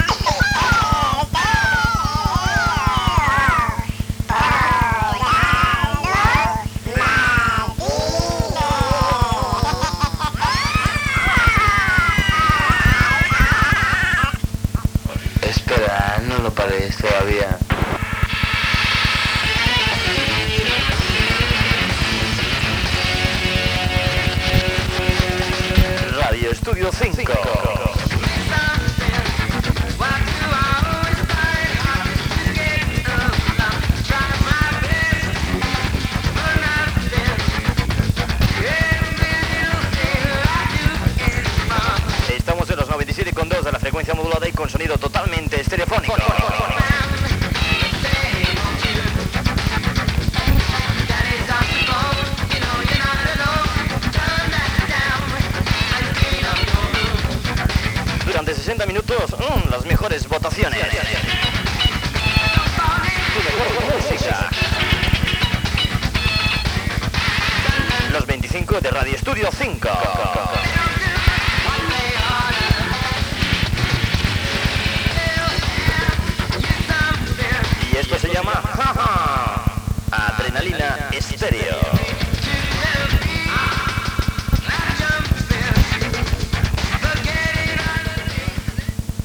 75cf55c5777d975dfba6f1f7964b3b5f051965c4.mp3 Títol Radio Estudio 5 Emissora Radio Estudio 5 Titularitat Tercer sector Tercer sector Musical Nom programa Los 25 de Radio Estudio 5 Descripció Identificació de l'emissora i del programa.